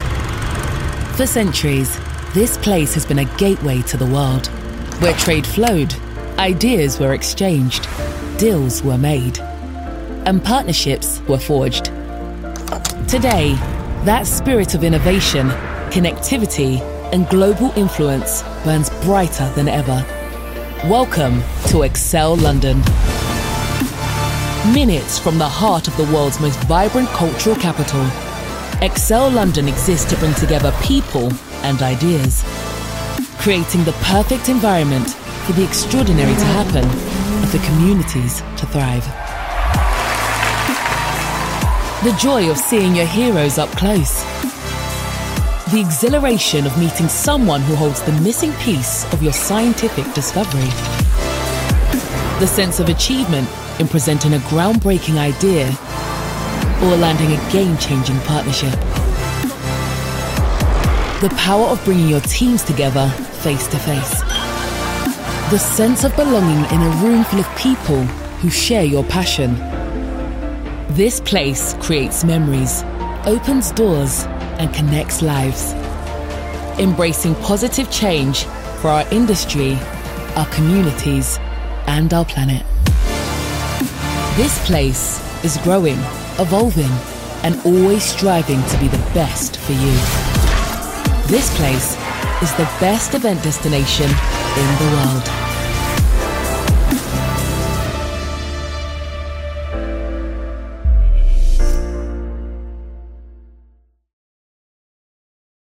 English (British)
Warm, Commercial, Deep, Friendly, Corporate
Corporate